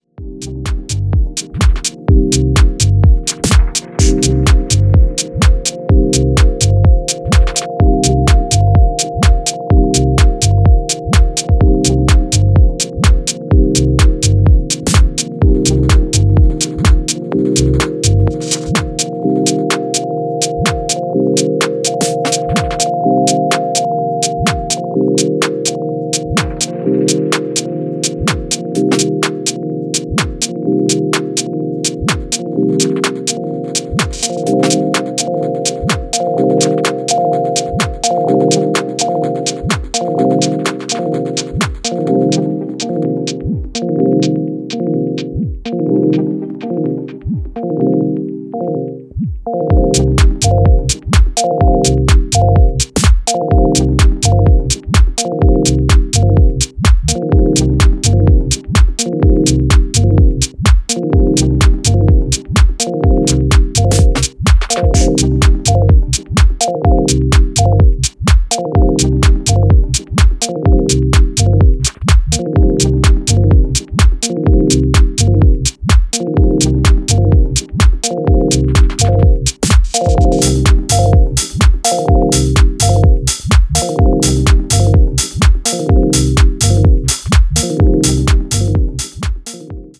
催眠効果たっぷりの転がるようなローズ・ピアノがジワリと効いてくる
秀逸なミニマル・ハウス群を展開しています。